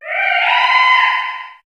Cri d'Amagara dans Pokémon HOME.